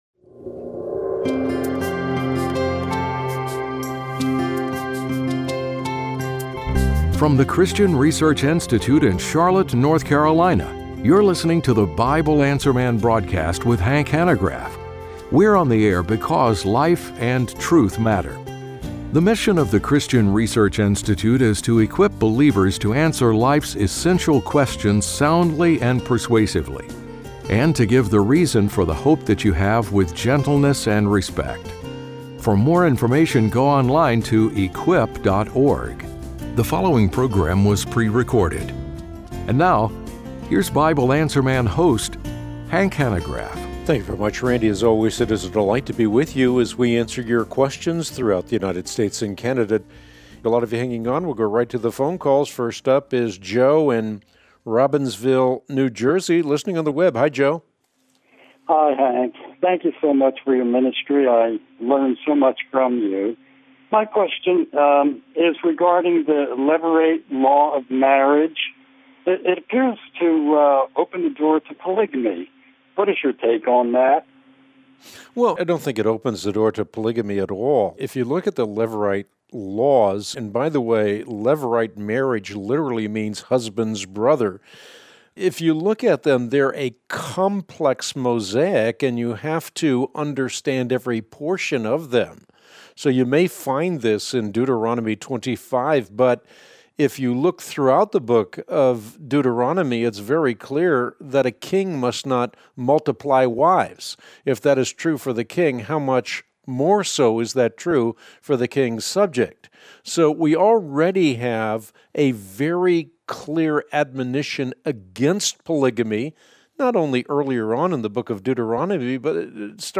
On today’s Bible Answer Man broadcast (08/01/25), Hank answers the following questions: